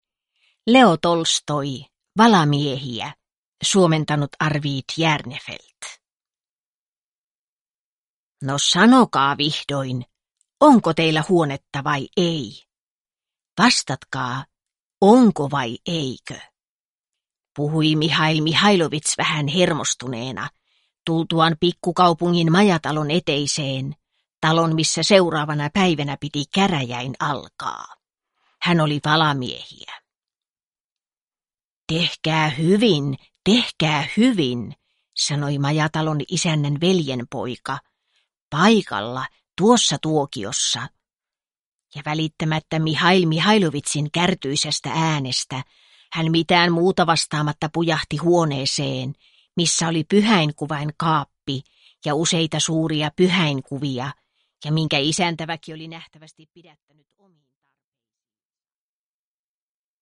Valamiehiä (ljudbok) av Leo Tolstoi